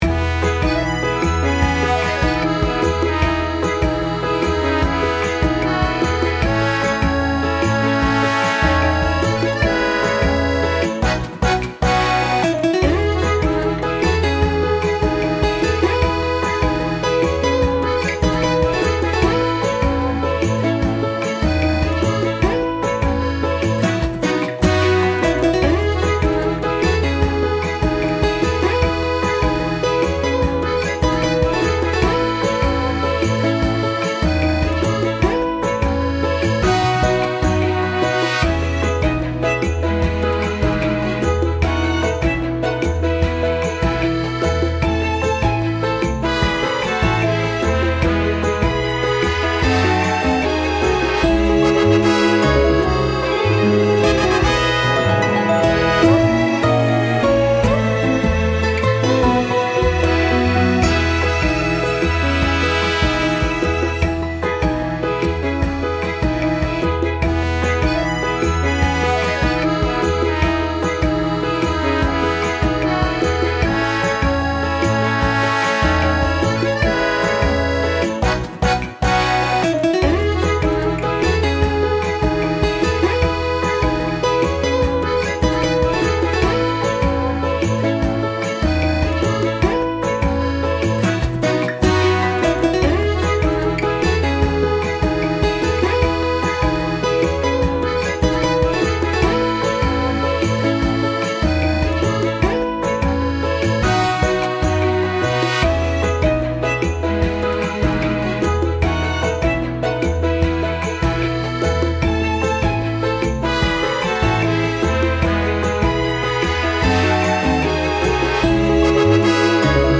サルサ系のBGMを作ろうとしたら、結構ポップな雰囲気になってしまいました。
穏やかな町並みをテーマに、軽快さと爽やかさを意識して制作しました。
平和的な場面に合いそうです。
2. 楽しい
4. おだやか
5. トランペット
7. トロンボーン
10. トゥンバオ
11. ストリングス
14. ラテン
17. ベース
18. コンガ
19. クラベス
22. サルサ
25. ノリが良い
26. ティンバレス
27. ピアノ